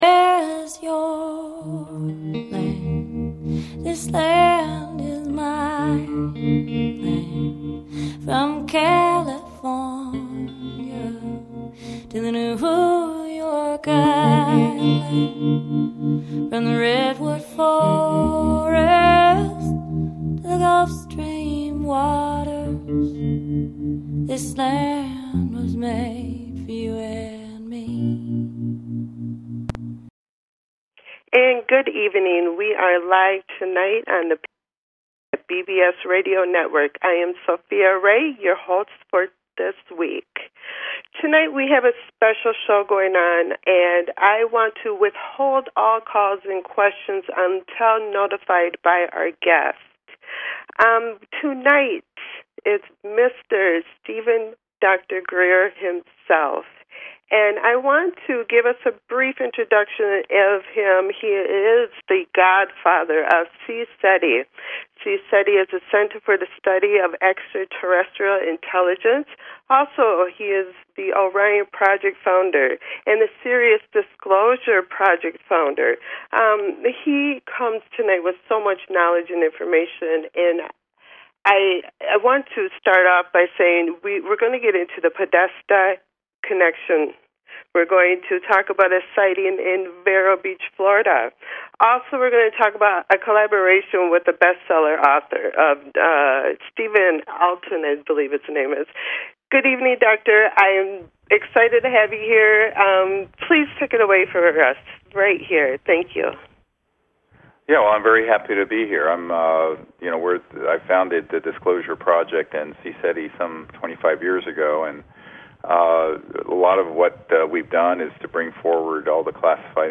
Talk Show Episode, Audio Podcast, The People Speak and Guest Steven Greer on , show guests , about Steven Greer,Disclosure Project,Sirius Project,UFO,Alien, categorized as Education,News,Paranormal,Theory & Conspiracy
The show features a guest interview from any number of realms of interest (entertainment, science, philosophy, healing, spirituality, activism, politics, literature, etc.).